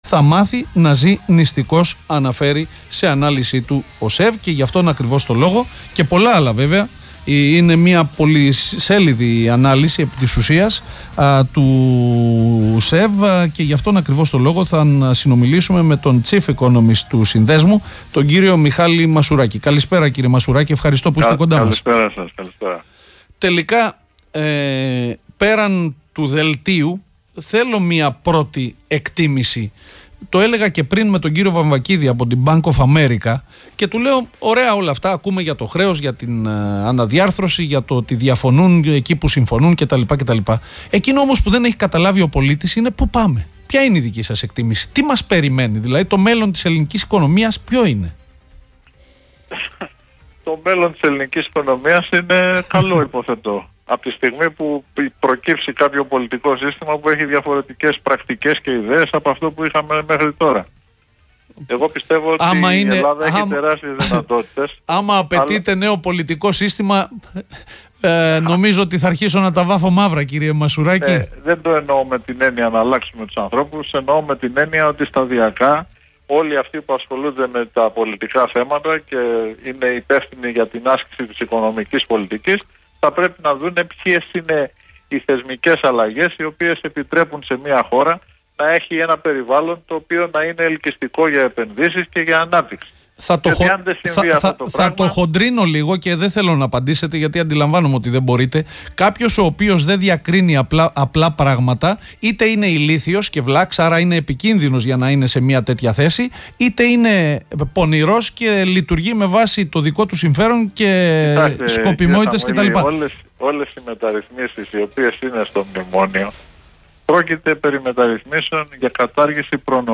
μιλά για το μέλλον της ελληνικής οικονομίας στα ΠΑΡΑΠΟΛΙΤΙΚΑ FM